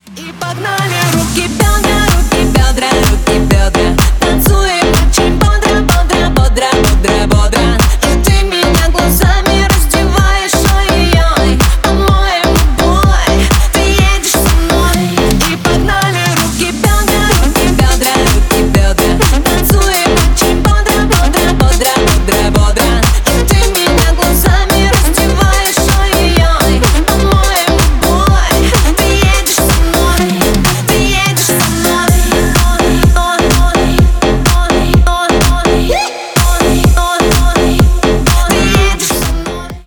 веселые
поп